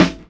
• Original Snare Single Hit F Key 105.wav
Royality free snare tuned to the F note. Loudest frequency: 969Hz
original-snare-single-hit-f-key-105-qcU.wav